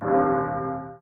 Warning sound for end of invincibility.
end_of_invincibility_warning.ogg